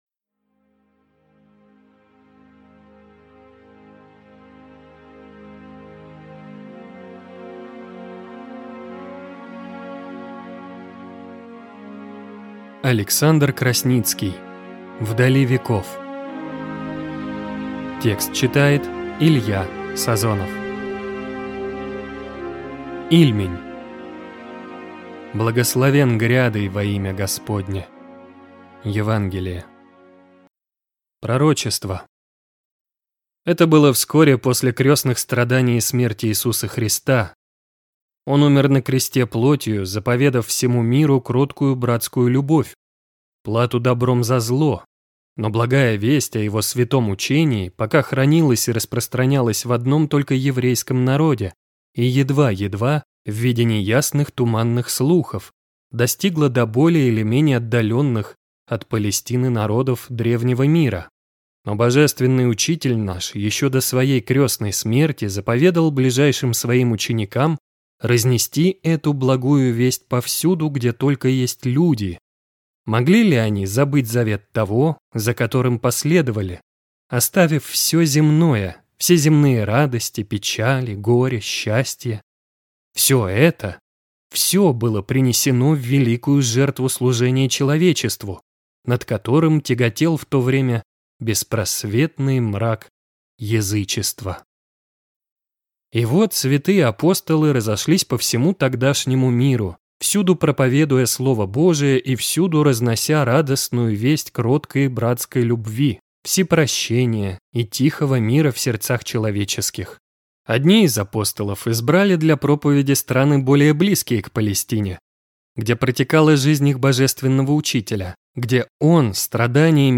Аудиокнига В дали веков | Библиотека аудиокниг